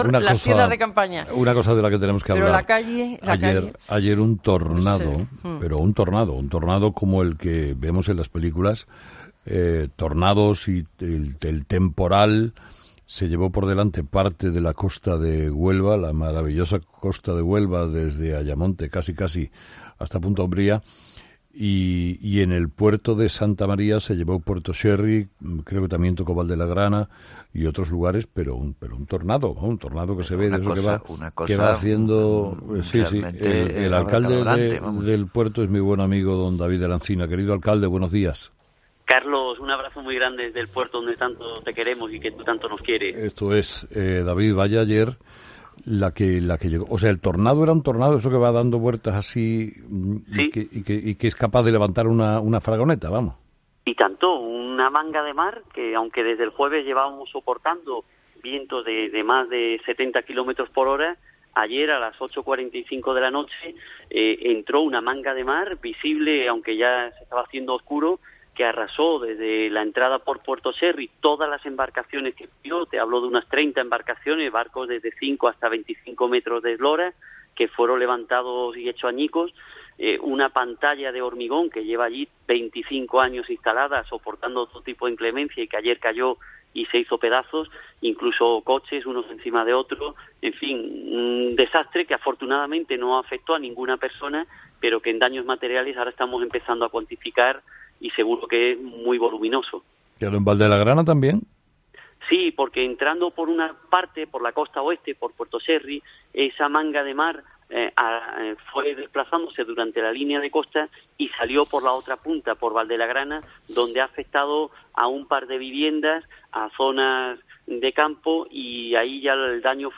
Entrevista al alcalde del Puerto de Santa María
Debido a los destrozos en coches, barcos y locales comerciales con los que ha amanecido este lunes El Puerto de Santa María (Cádiz), causados por un tornado, ha sido entrevistado en ‘Herrera en COPE’ David de la Encina, alcalde del municipio.